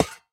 Minecraft Version Minecraft Version 25w18a Latest Release | Latest Snapshot 25w18a / assets / minecraft / sounds / block / trial_spawner / step2.ogg Compare With Compare With Latest Release | Latest Snapshot
step2.ogg